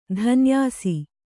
♪ dhanyāsi